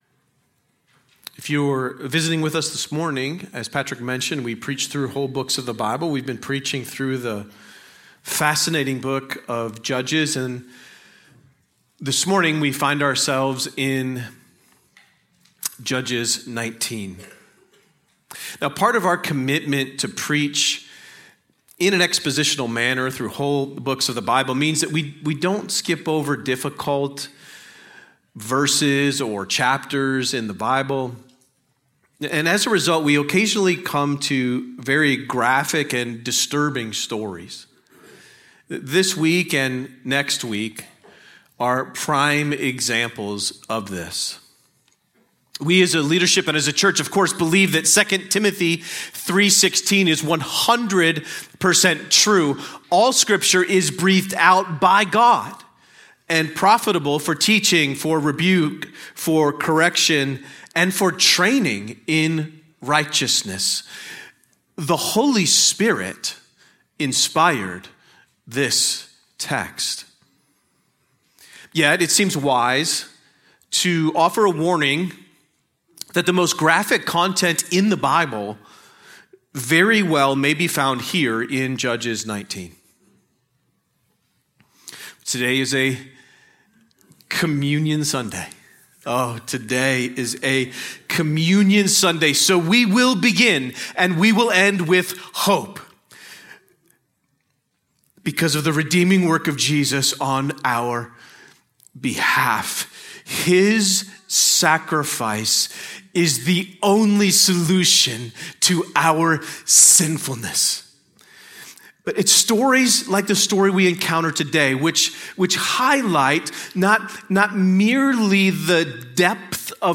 A sermon on Judges 19:1-21